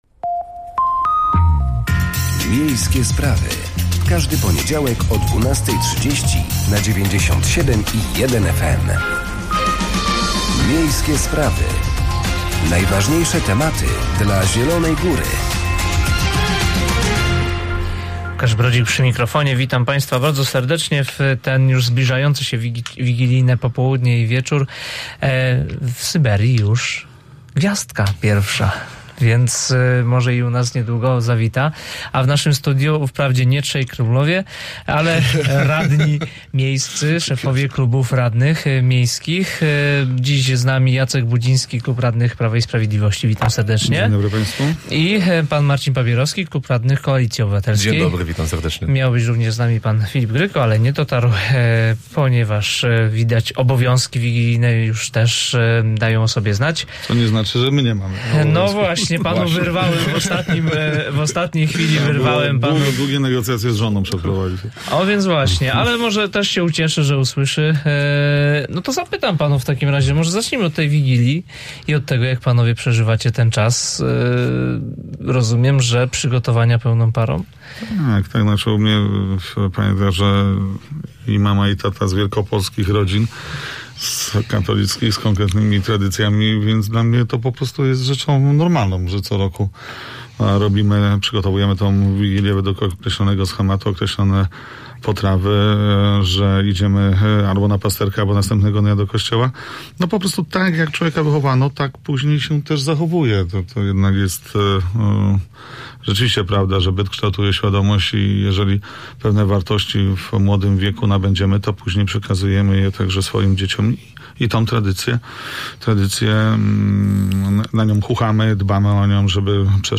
miejskie-sprawy-rozmowa.mp3